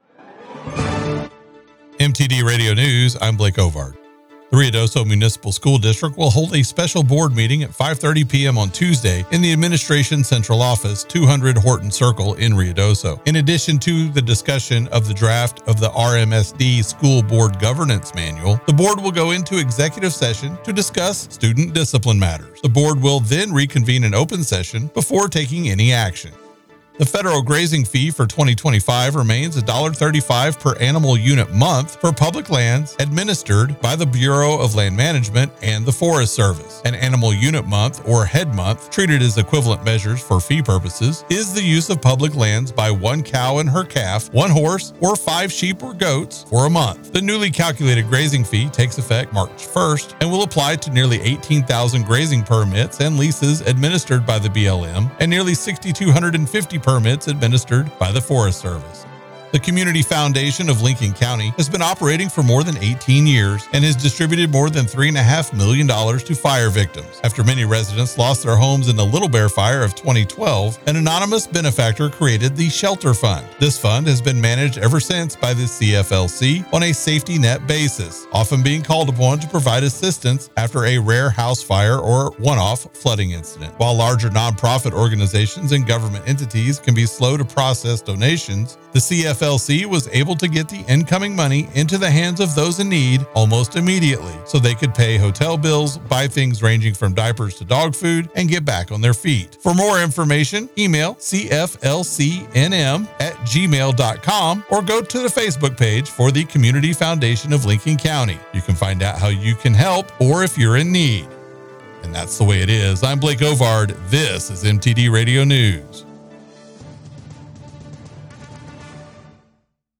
Mix 96.7 News – Ruidoso and Lincoln County